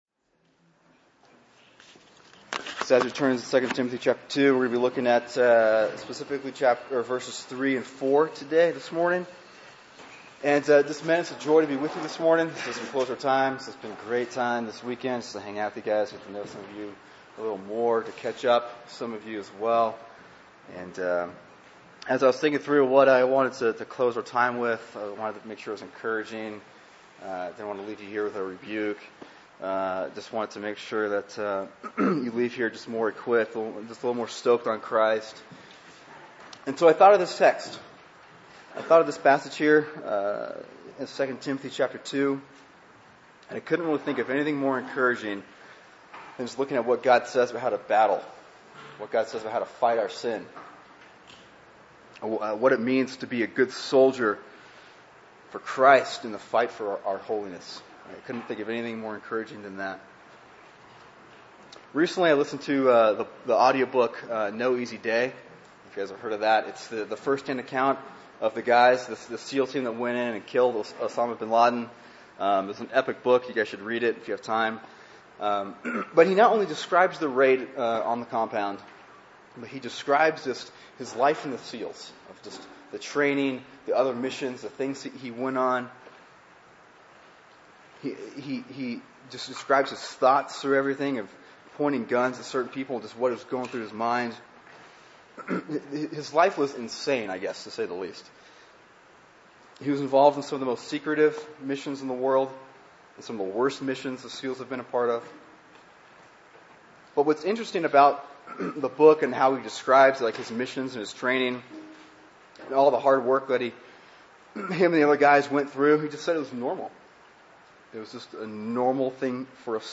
Cornerstone/TVBC Men’s Retreat January 20, 2013